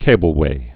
(kābəl-wā)